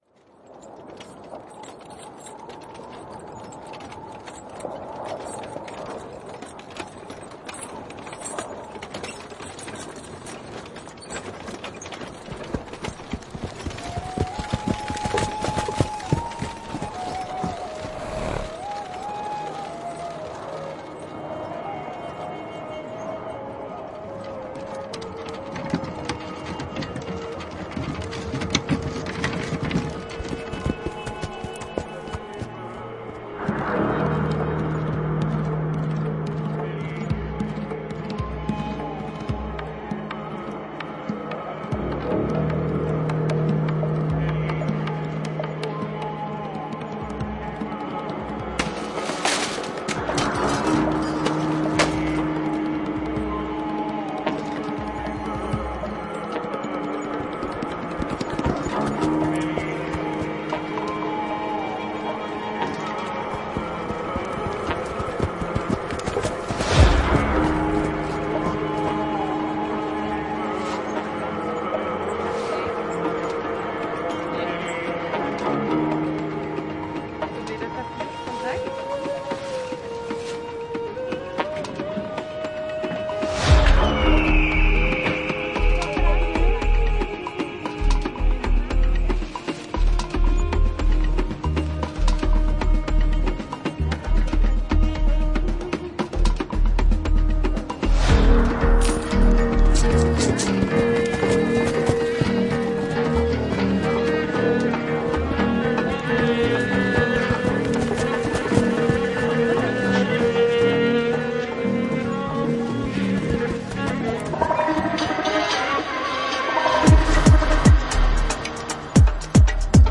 Звуковые инсталляции, наполняющие территорию Музея-заповедника «Казанский Кремль», окликают современность из прошлого, вторят пестрому многоголосию истории.
Инсталляция «Торговые берега»
На протяжении столетий торговые пути со всех концов света шли через Казань. Казанский Кремль был точкой притяжения большого шумного многоголосого города на берегу великой реки Волги, местом, где смыкались и переливались в яркой палитре великое и малое, миры – духовный и бытовой, судьбы государств и людей.